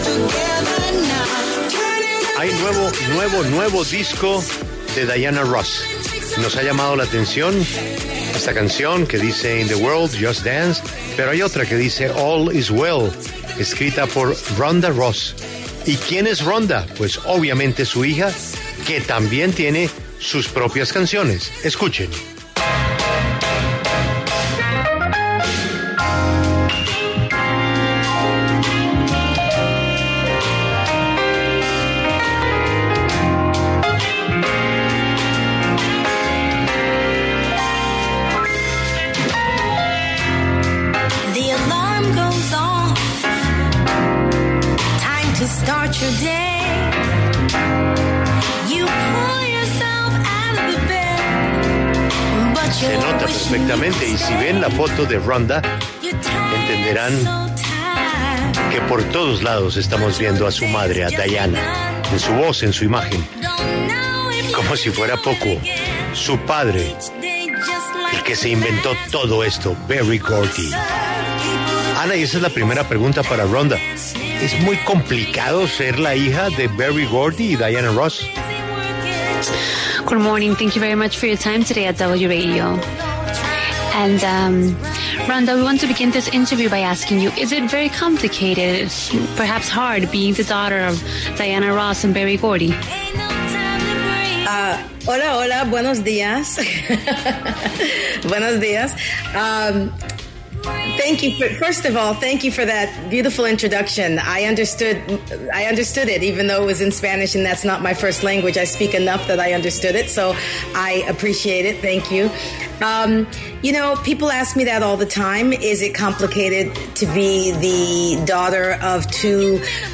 Rhonda Ross, cantante estadounidense, habló en La W sobre la primera producción musical de su madre Diana Ross en 14 años y su legado como artista.